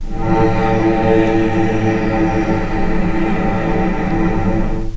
vc_sp-A2-pp.AIF